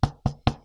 Impact
Wooden Knock.wav